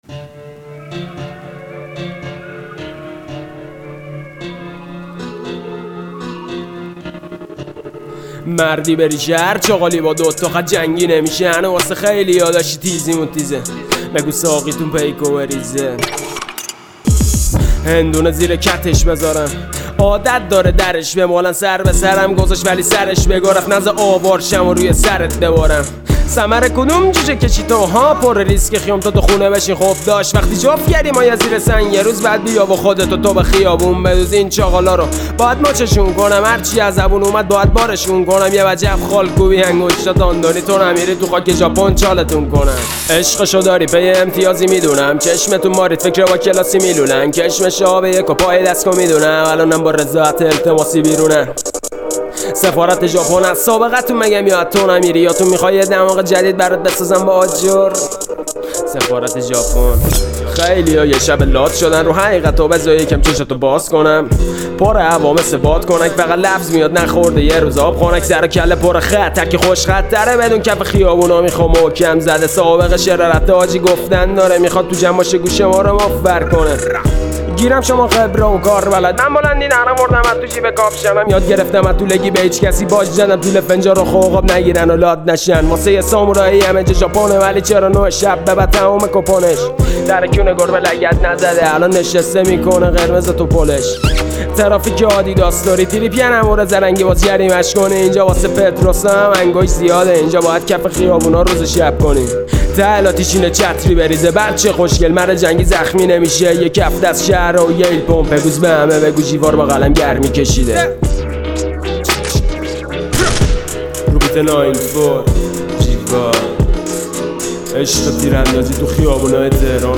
رپ جدید